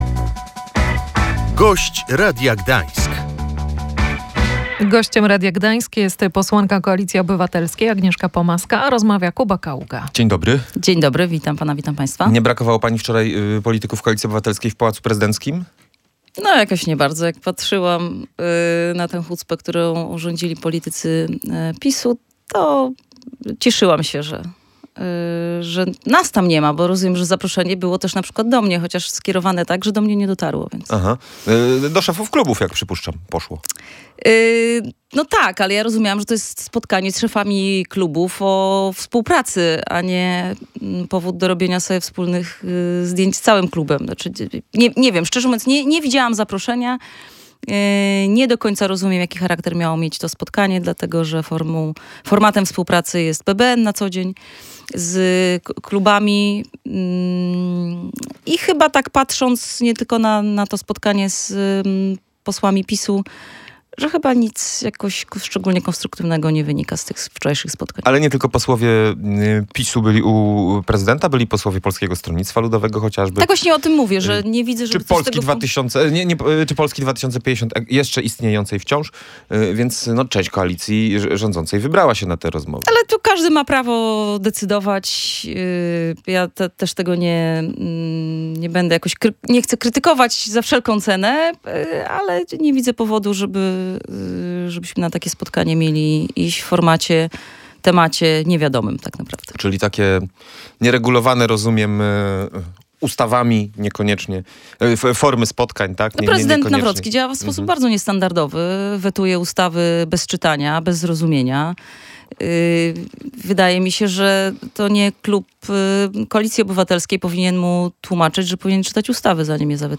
Jesteśmy na dobrej drodze, by siedzibę Orlen Neptun przenieść do Gdańska – mówiła w Radiu Gdańsk Agnieszka Pomaska, posłanka Koalicji Obywatelskiej. Dodała, że wkrótce powinna zostać ogłoszona decyzja w tej sprawie.